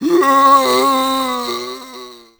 c_zombim4_dead.wav